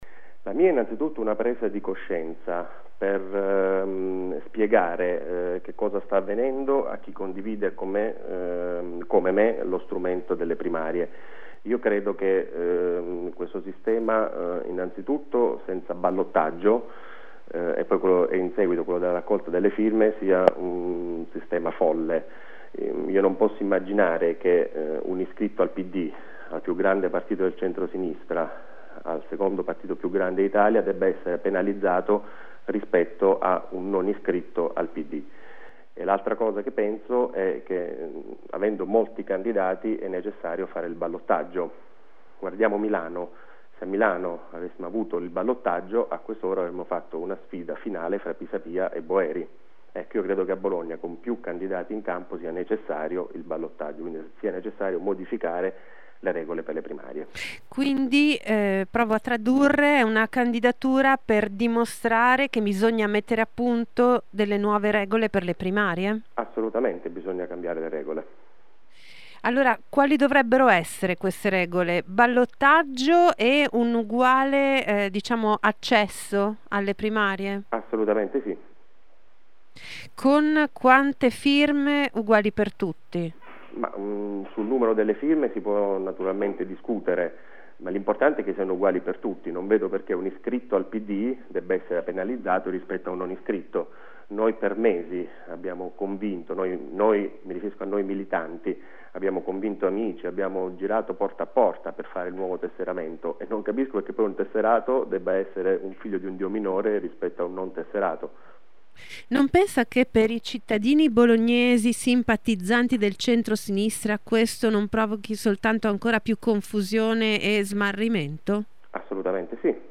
“Le regole sono nello Statuto”, spiega Donini intervistato da Telecentro, e dire che “se non si cambiano le regole si restituisce la tessera è contrario all’etica della responsabilità”.